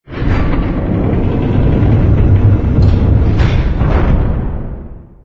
ring_open.wav